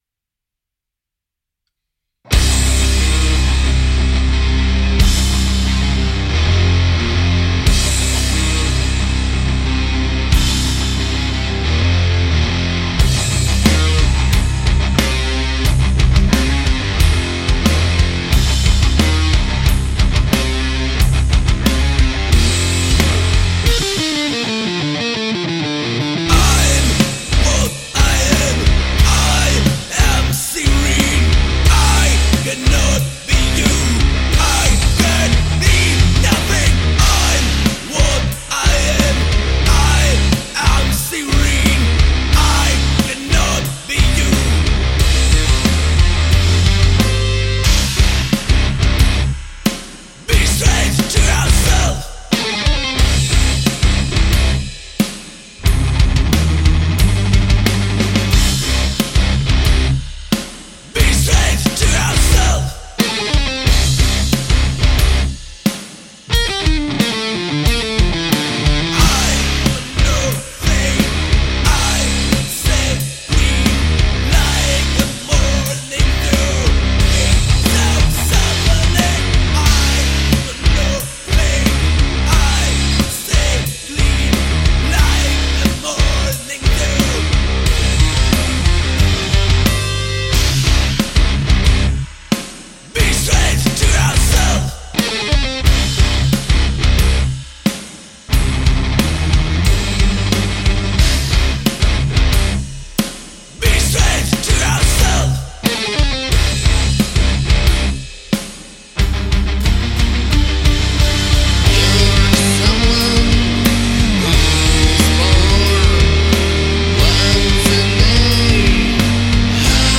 Žánr: Metal/HC
Crossover-metal